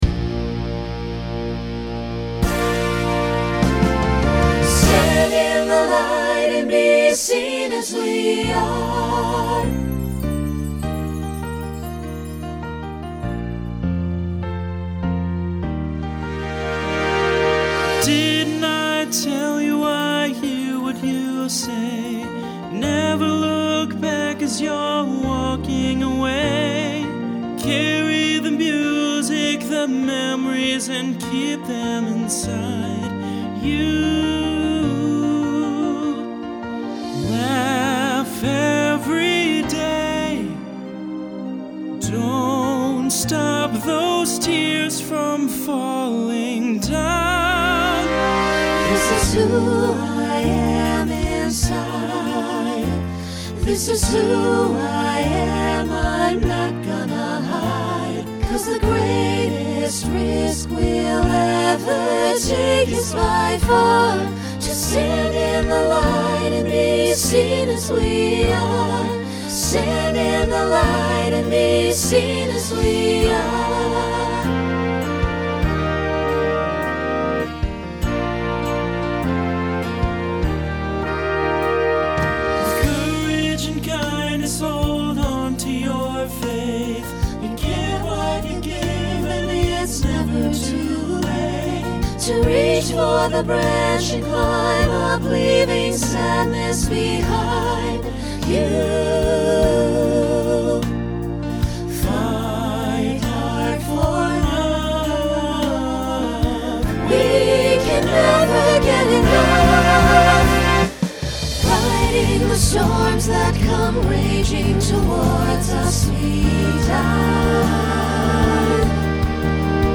Genre Pop/Dance Instrumental combo
Function Ballad Voicing SATB